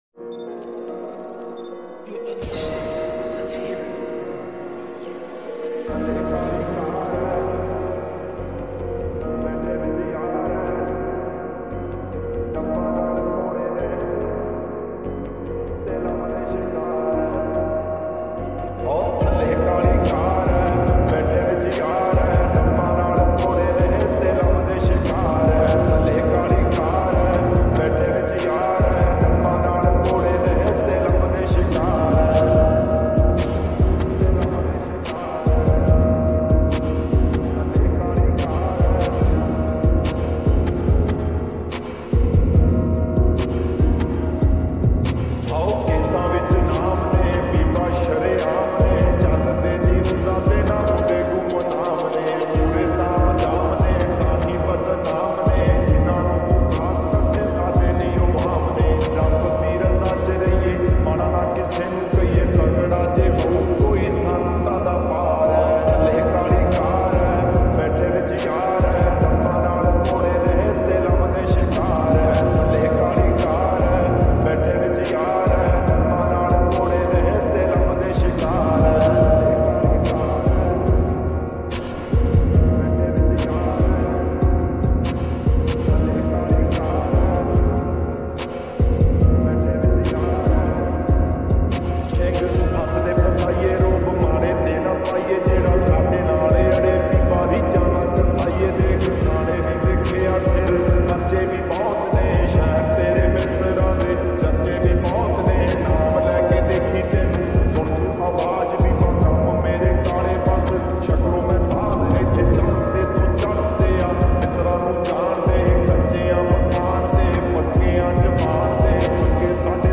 slowed down reverb